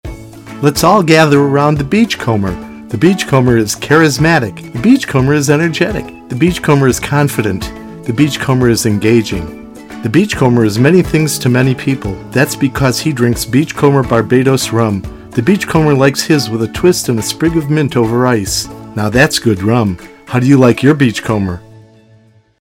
• Commercials
With a tone that’s clear, friendly and easy to listen to, I help brands share their stories, reach their audiences, and create personal experiences.
Beachcomber_with-Music.mp3